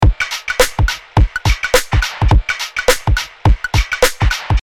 105BPM [Glitch Hop & Moombahton]
Funky yet powerful drums that slap with monstrous groove! These genres require larger than life drums without a doubt!